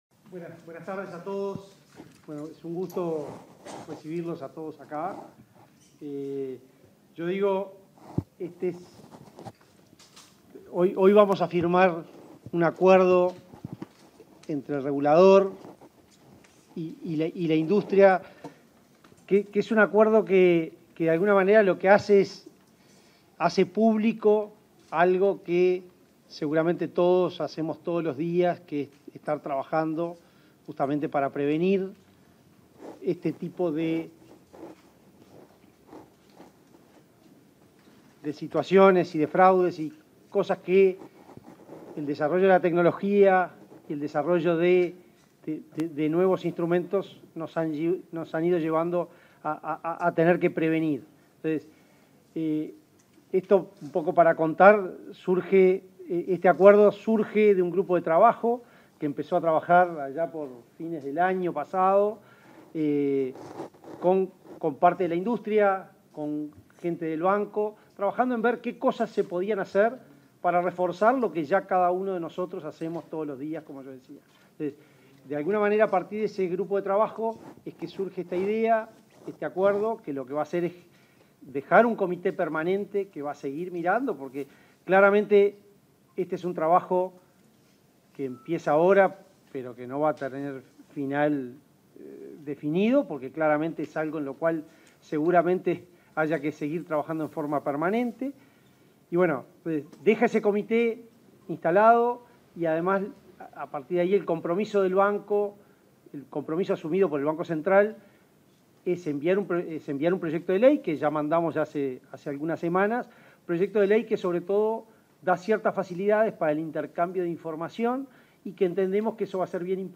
Palabras del presidente del BCU, Diego Labat
El Banco Central del Uruguay (BCU), instituciones de intermediación financiera, instituciones emisoras de dinero electrónico, Urutec y la Unidad de Defensa al Consumidor del Ministerio de Economía y Finanzas firmaron un acuerdo para la prevención de fraudes a cuentas mediante medios informáticos. En el acto disertó el presidente del BCU, Diego Labat.